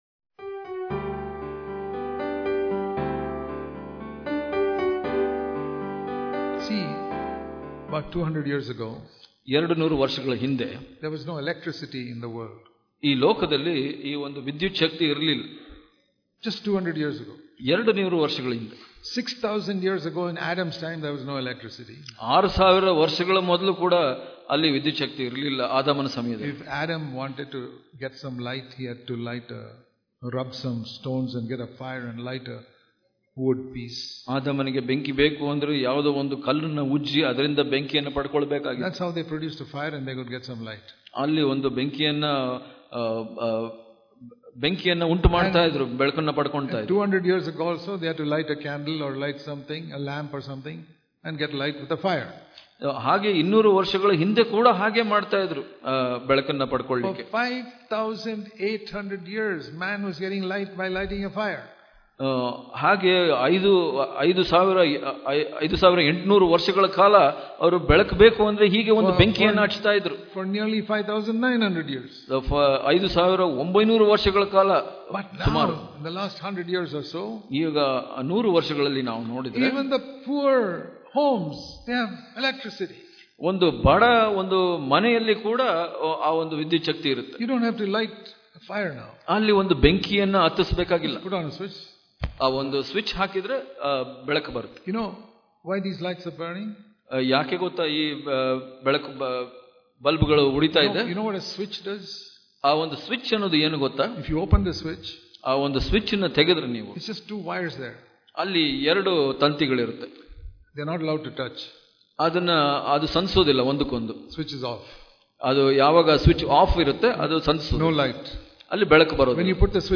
February 8 | Kannada Daily Devotion | Under grace sin will not rule over us - Part 2 Daily Devotions